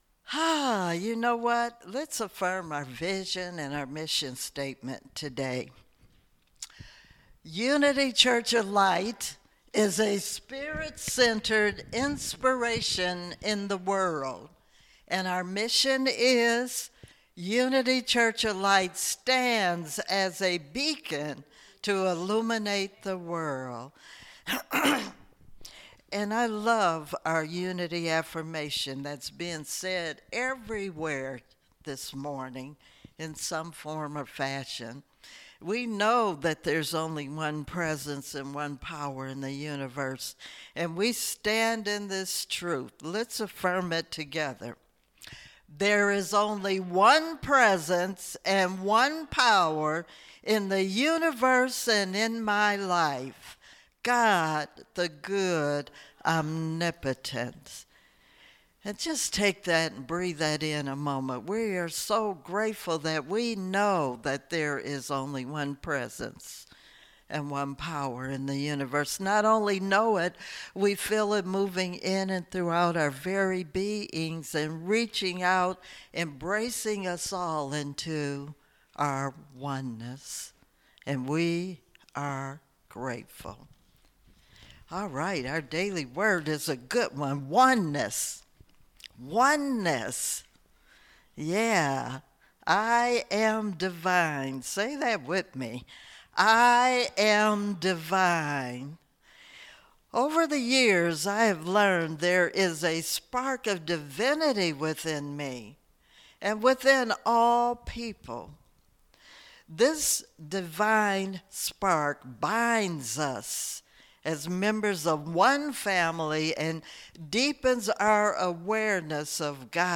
Series: Sermons 2021